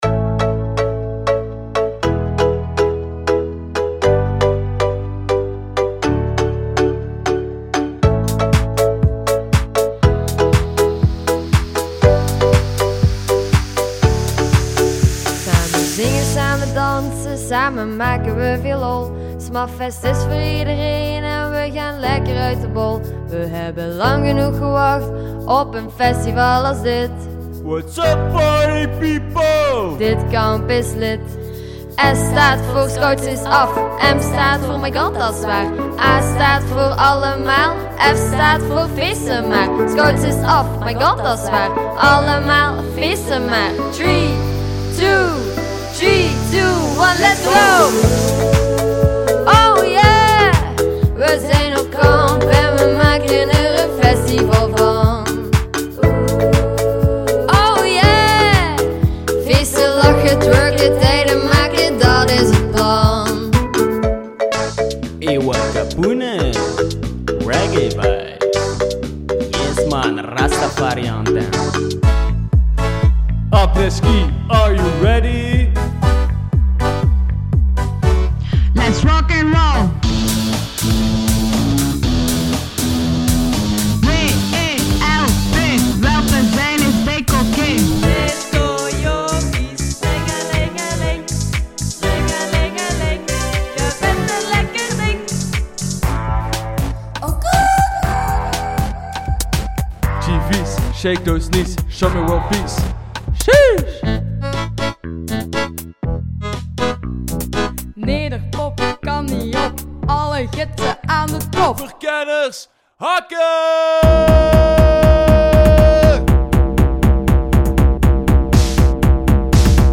Dit zijn de liedjes die onze scouts heeft gemaakt voor de groepskampen.